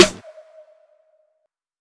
Bloop_Snr.wav